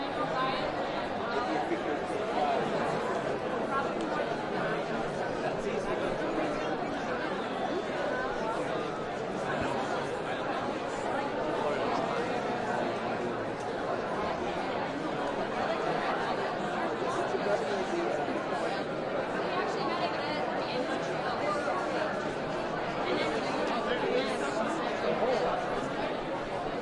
Montreal » crowd int medium students murmur walla large theatre active cinema politica Concordia short Montreal, Canada
描述：crowd int medium students murmur walla large theatre active cinema politica Concordia short Montreal, Canada.flac
标签： Montreal active murmur short students large int crowd theatre medium Canada walla
声道立体声